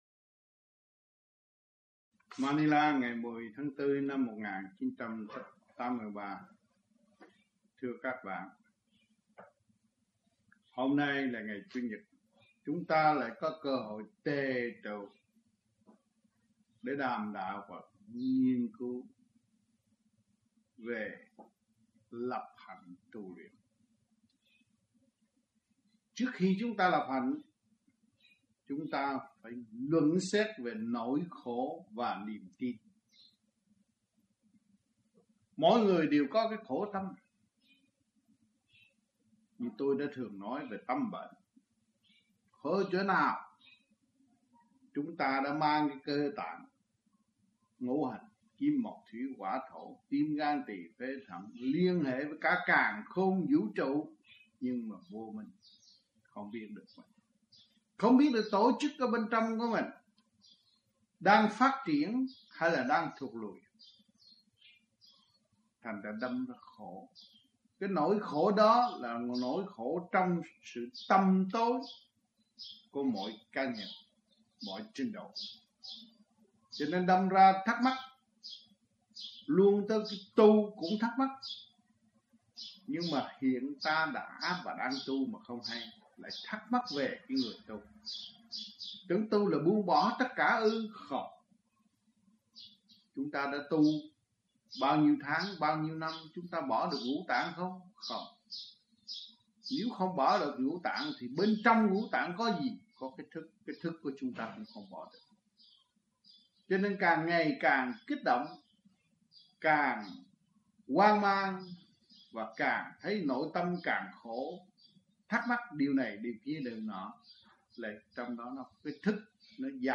Băng Giảng